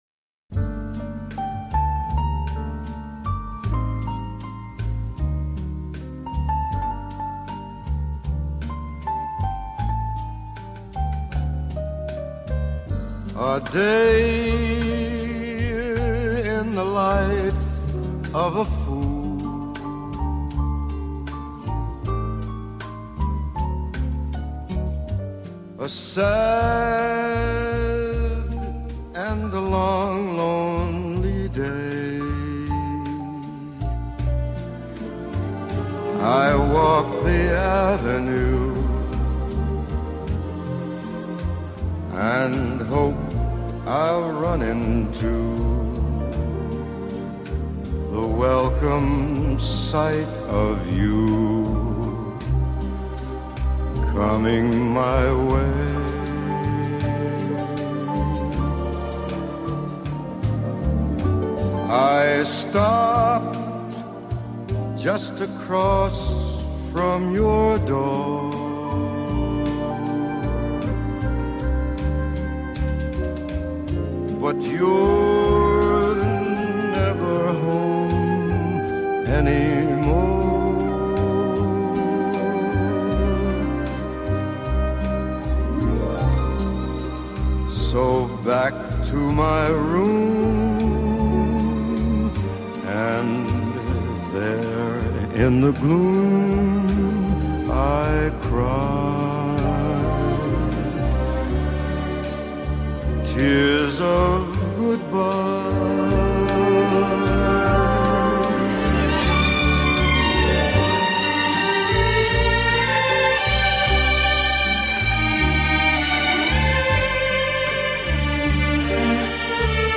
Инструментальное исполнение